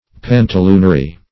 Search Result for " pantaloonery" : The Collaborative International Dictionary of English v.0.48: Pantaloonery \Pan`ta*loon"er*y\, n. 1.